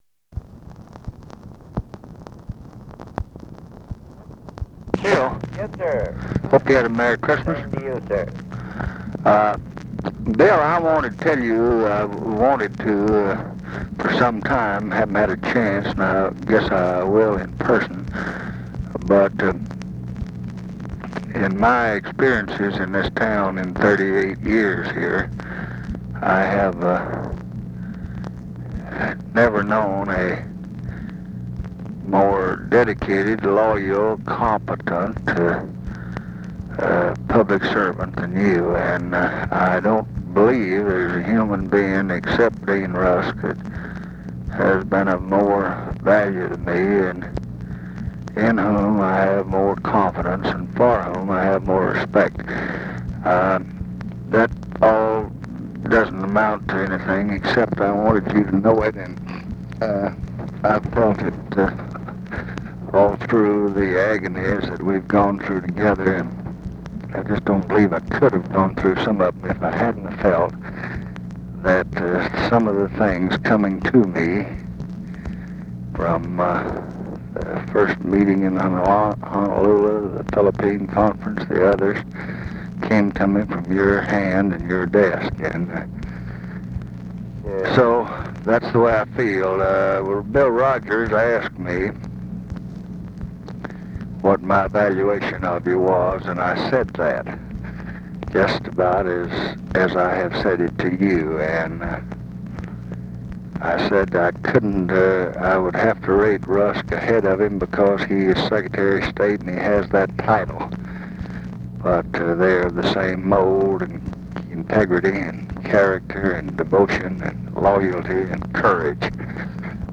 Conversation with WILLIAM BUNDY, December 25, 1968